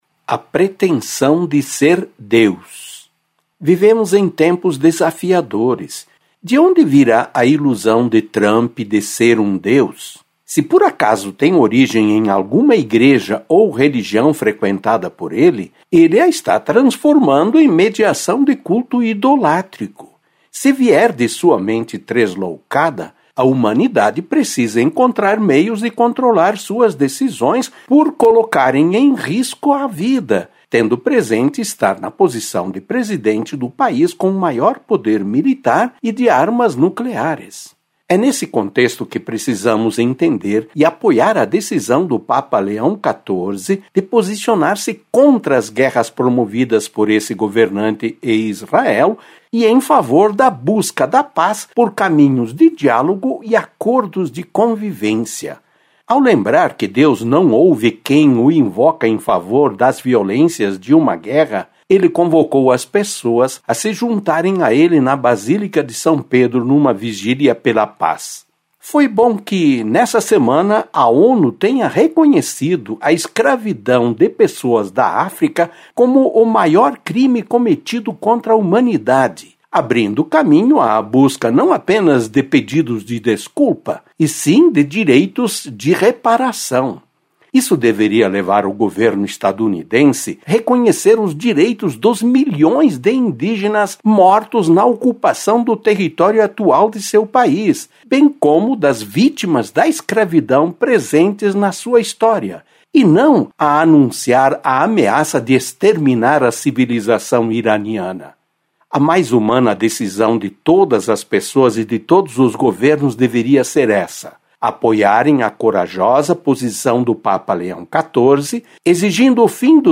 Acompanhe o Editorial